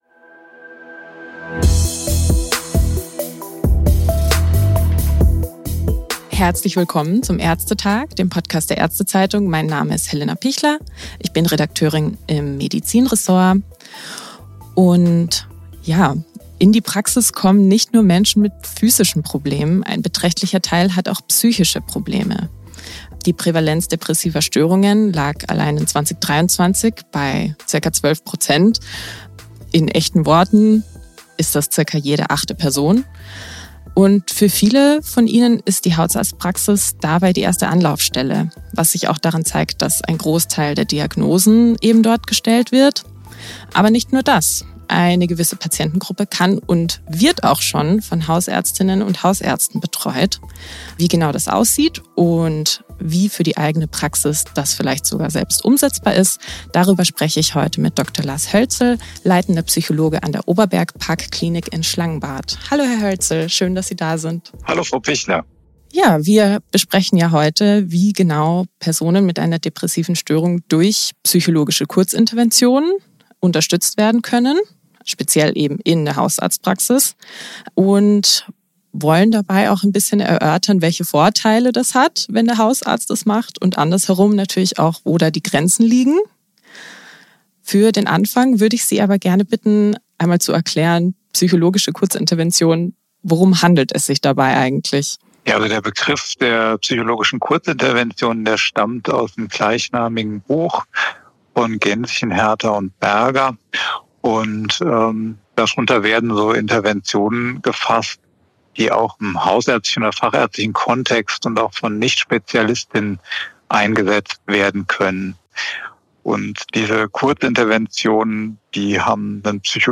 Um zu zeigen, wie umsetzbar das PLT tatsächlich ist, spielen wir eine Session nach und erklären das Vorgehen Schritt für Schritt.